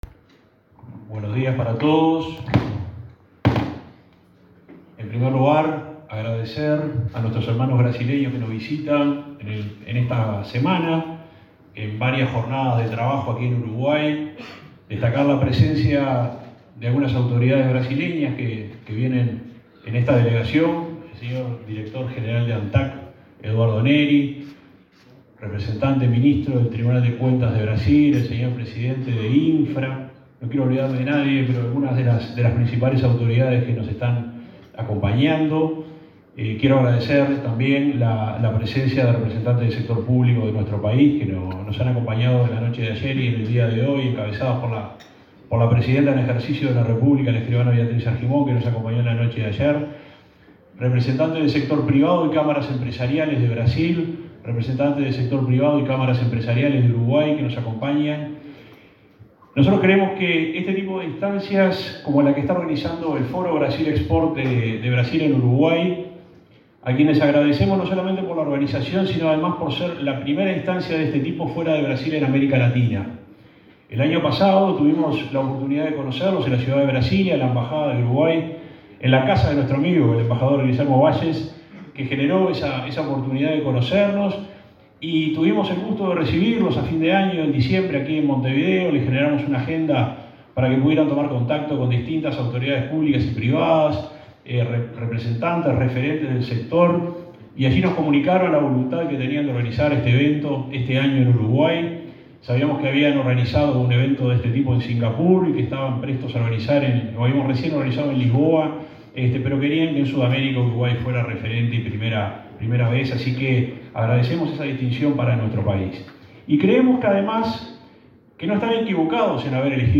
Palabras del subsecretario de Transporte, Juan José Olaizola
El subsecretario de Transporte, Juan José Olaizola, participó, este martes 12 en Montevideo, en el foro Mercosul Export, al que asistieron jerarcas y